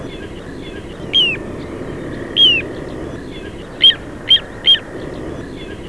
bird17.wav